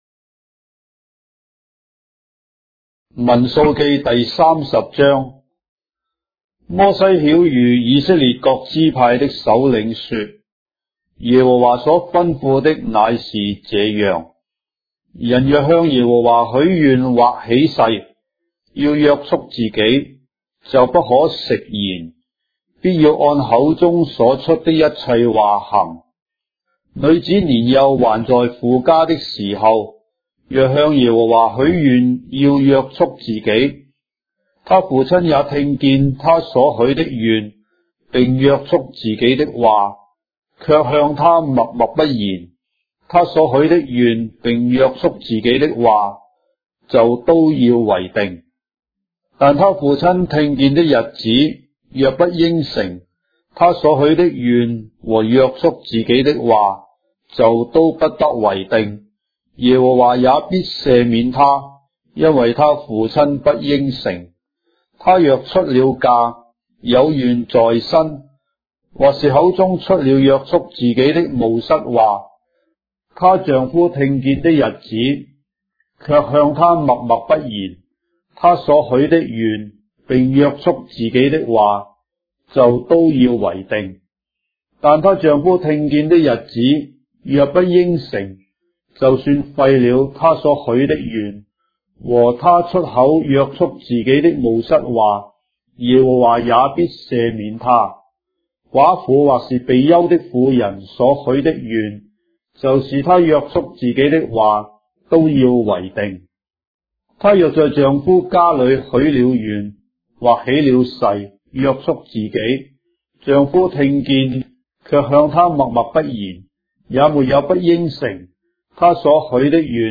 章的聖經在中國的語言，音頻旁白- Numbers, chapter 30 of the Holy Bible in Traditional Chinese